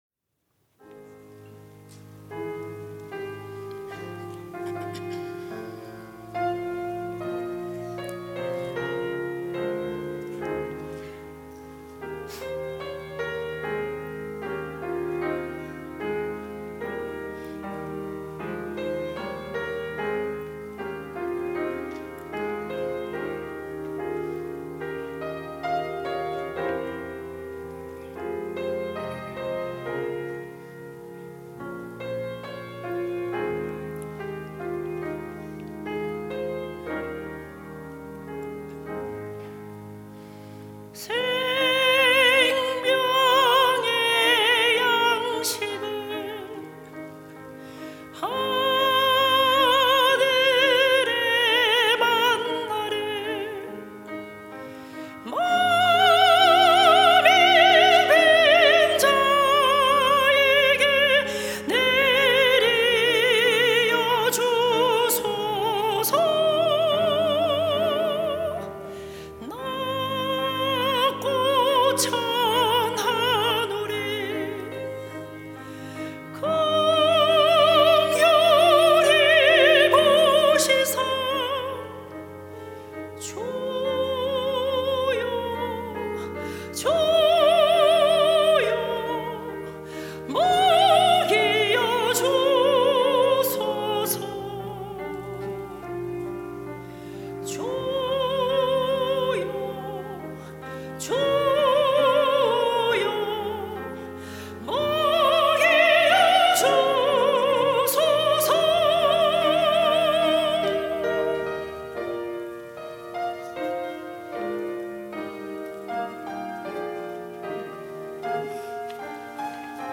시온(주일1부) - 생명의 양식
찬양대